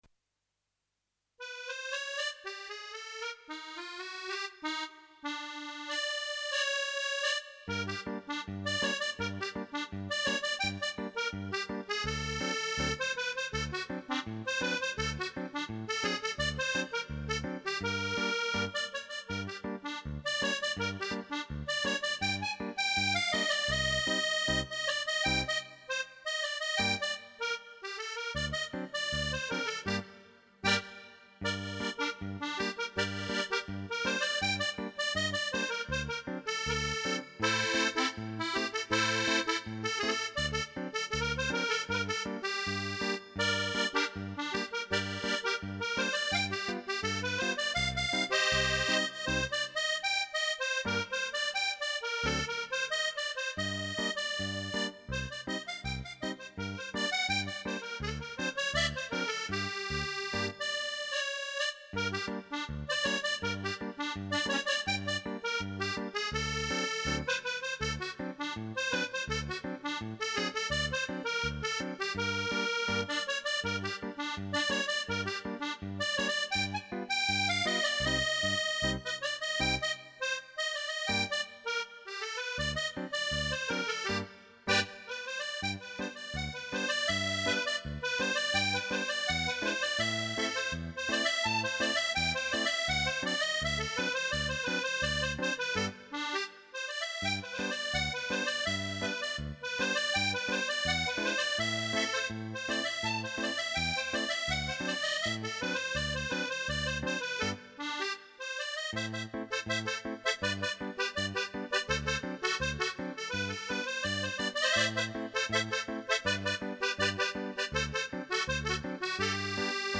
Accordion solos
accordeon_samba.mp3